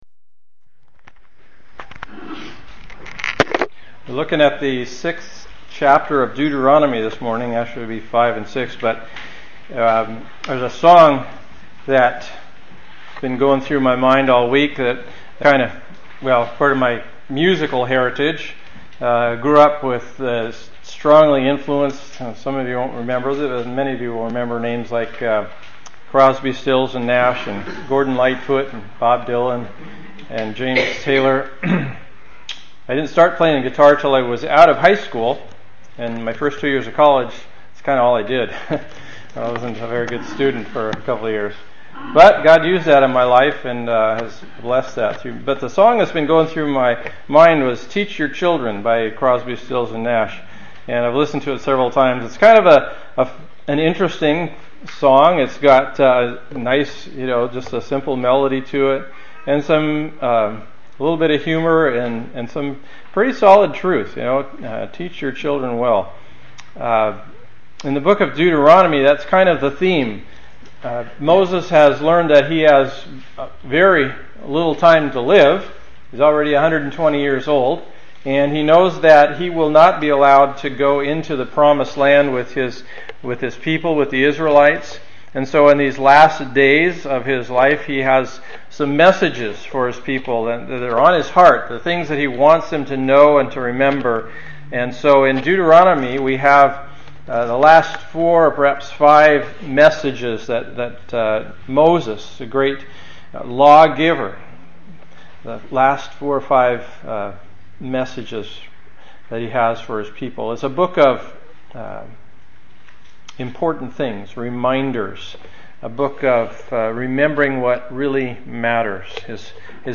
Sermon Recordings - SAN DE FUCA COMMUNITY CHAPEL